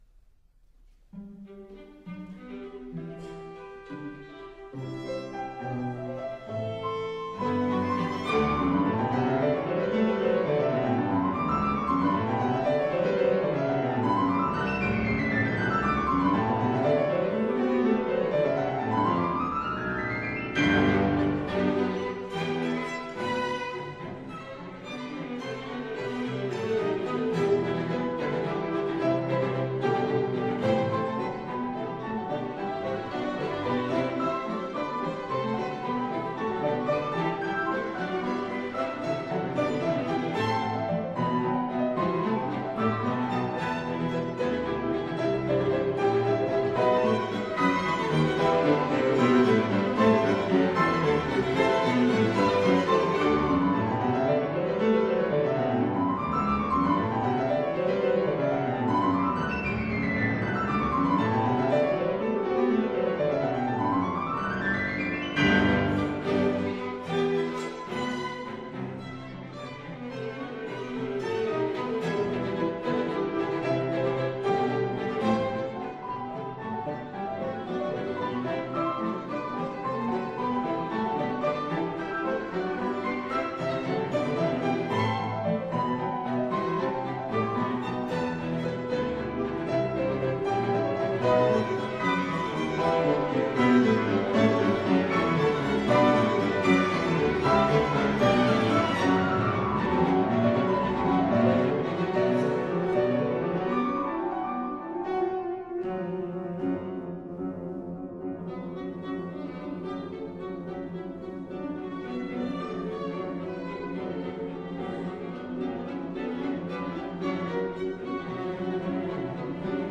It is a massive work in four movements and composed in the late Romantic style of Central Europe, Brahms in particular.
The second movement, Allegro giusto, features scale passages, especially prominent in the piano. This is not a light, fleet-footed scherzo, but more like a resolute march.